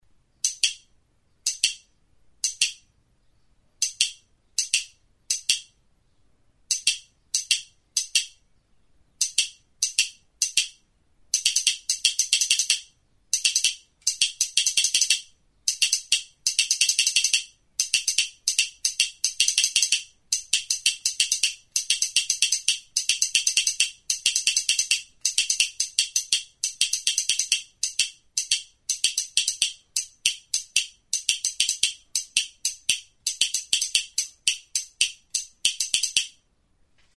Music instrumentsAHATEA; Clicking duck
Idiophones -> Plucked / flexible -> With sound board
Recorded with this music instrument.
Azpialdean altzairu malguzko txapa bat du eta palankatxo batekin eraginez hotsa ematen du, ahatearen kantuaren antzeko hotsa emanez.